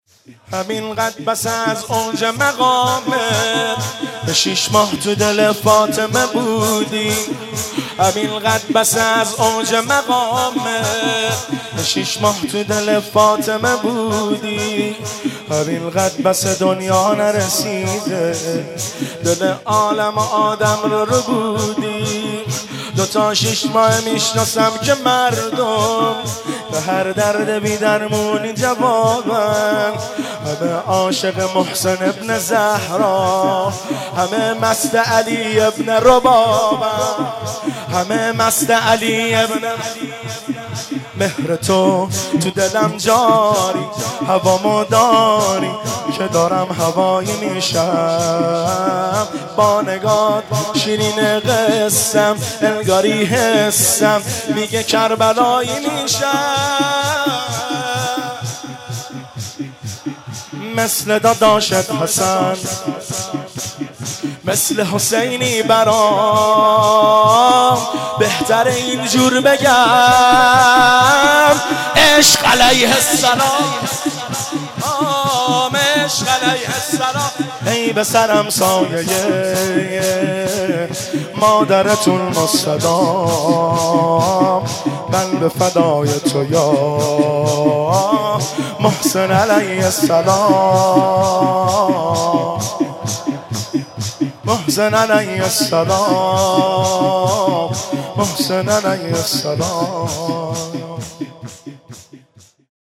مناسبت : شهادت حضرت فاطمه زهرا سلام‌الله‌علیها
قالب : شور